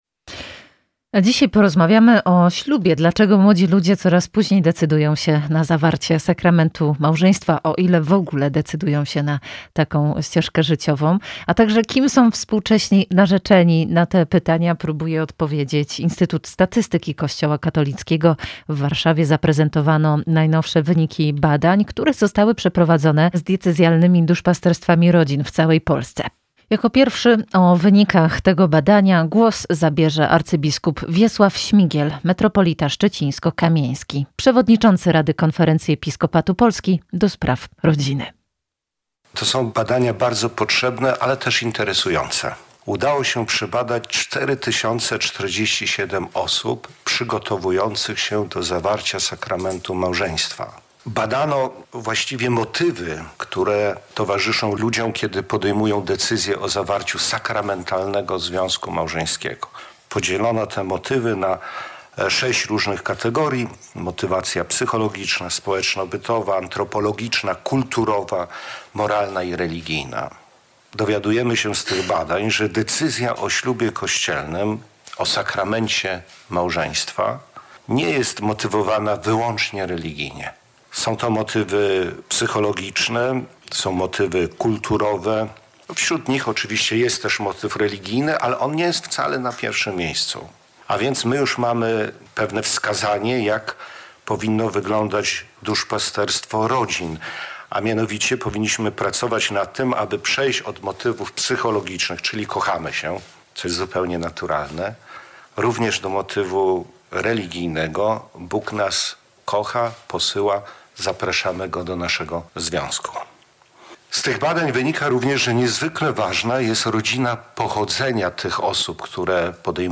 W konferencji wzięli udział: abp Wiesław Śmigiel, metropolita szczecińsko-kamieński, przewodniczący Rady Konferencji Episkopatu Polski ds. Rodziny